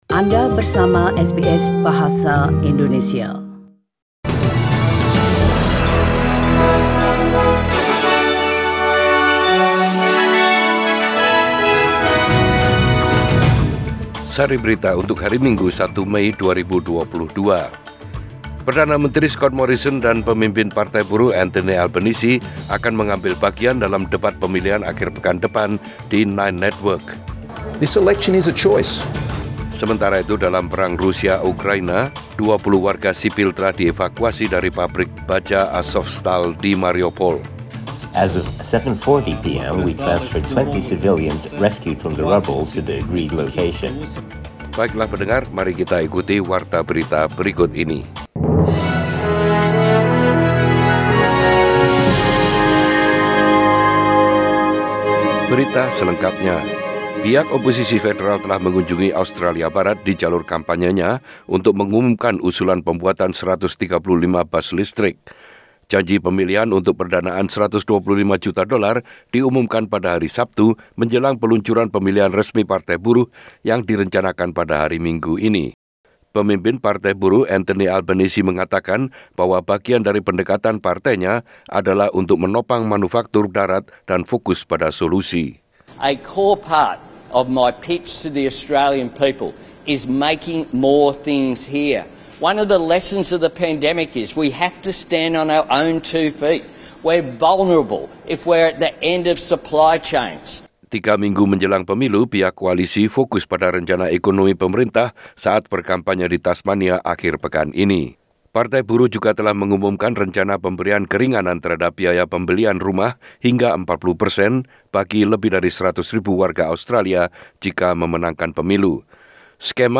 SBS Radio News in Bahasa Indonesia - 1 May 2022
Warta Berita Radio SBS Program Bahasa Indonesia.